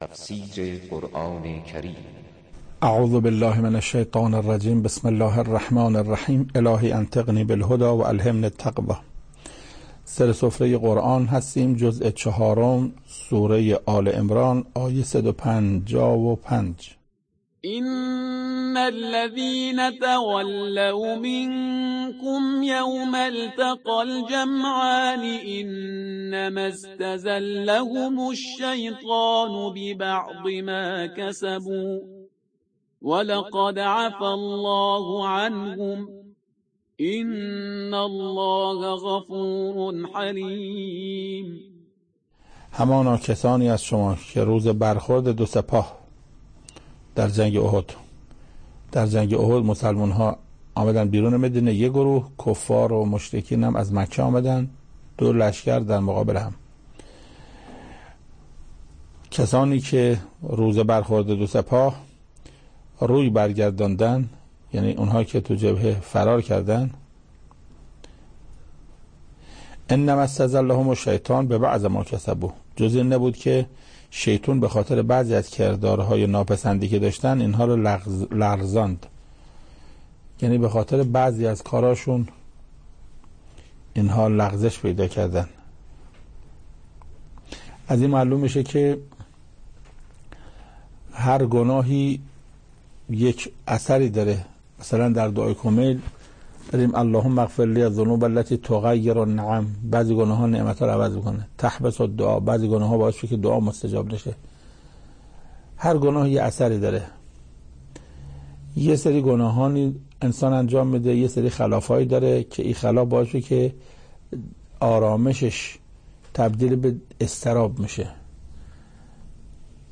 تفسیر صد و پنجاه و پنجمین آیه از سوره مبارکه آل عمران توسط حجت الاسلام استاد محسن قرائتی به مدت 7 دقیقه